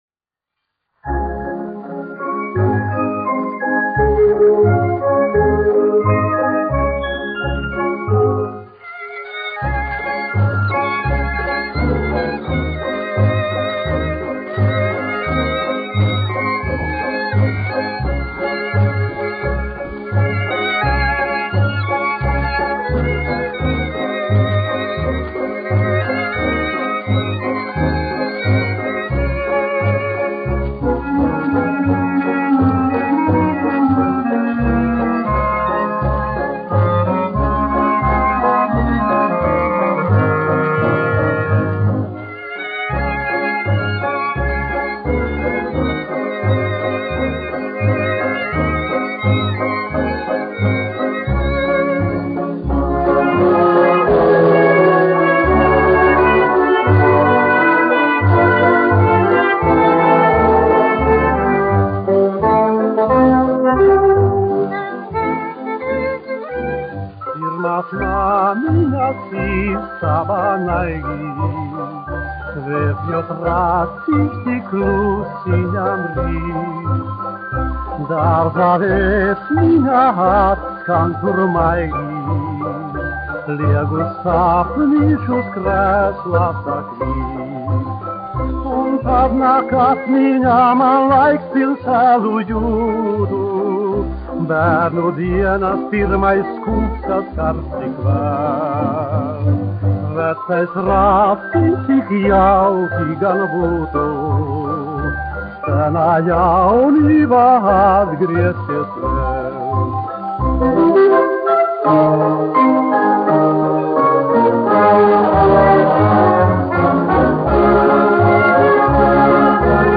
: analogs, 78 apgr/min, mono ; 25 cm
Populārā mūzika
Fokstroti
Skaņuplate